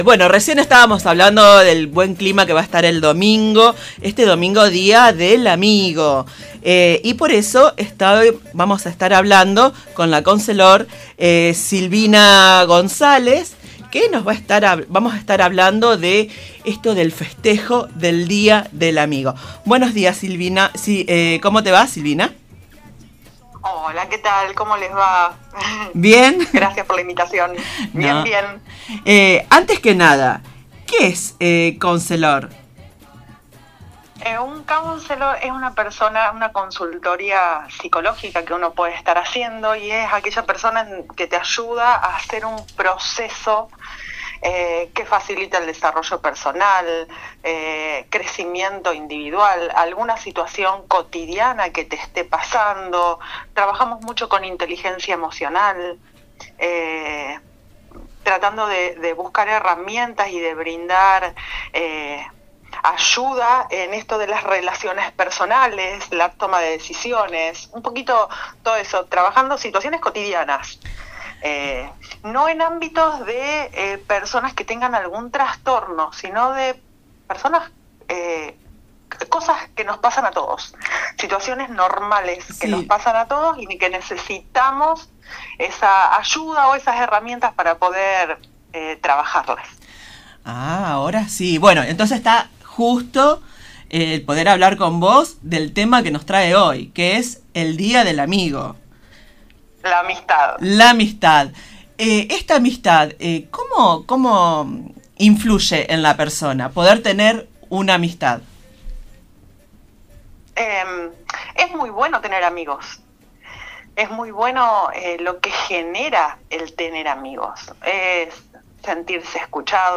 Con los años, una va depurando esos vínculos”, relató entre risas.
En el programa radial “La previa del finde”, por Radio Nueva Estrella 102.9